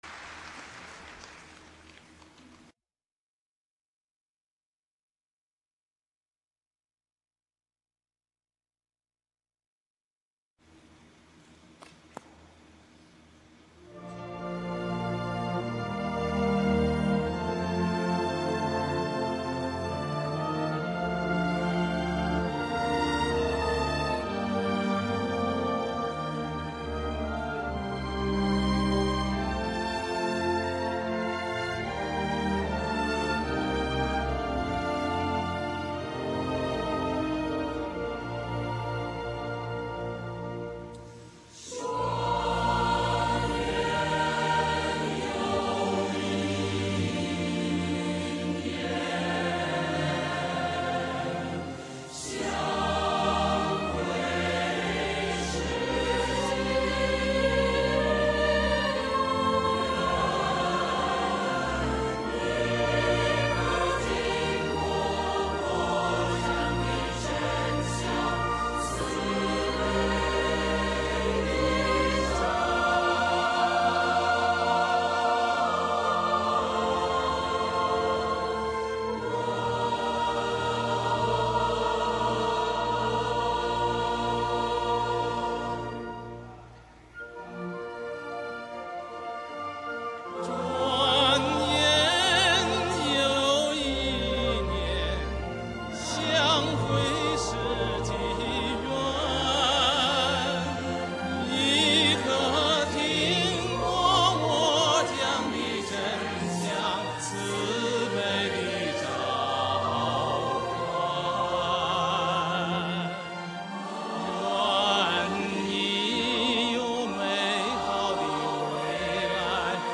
2006新唐人全球华人新年晚会节目选：祝愿(4分31秒) 2006.2.5
演唱：著名歌唱家关贵敏、欧洲“为你而来”合唱团，演奏：纽约交响乐团。